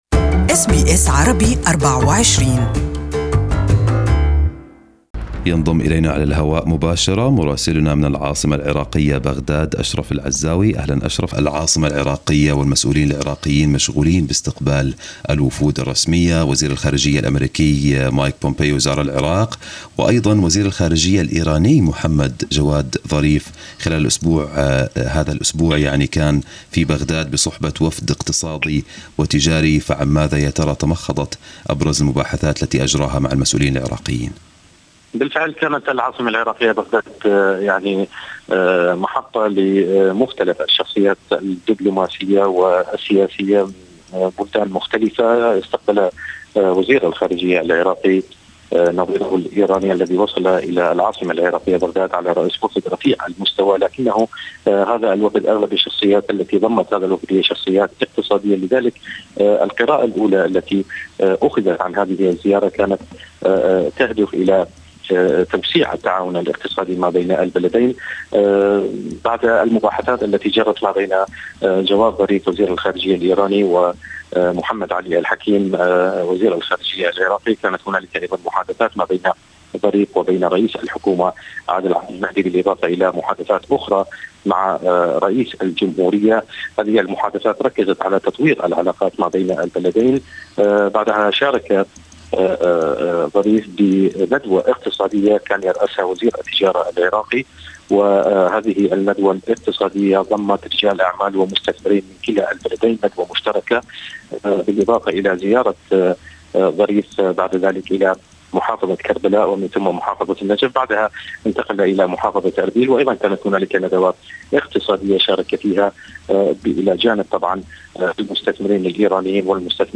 More in our interview with Iraq Correspondent